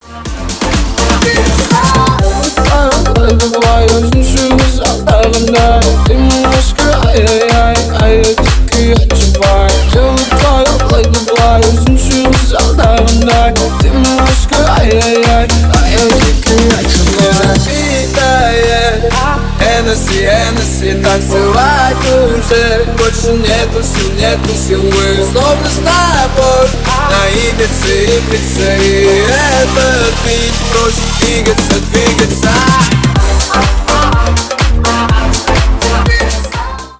Ремикс
Рэп и Хип Хоп
клубные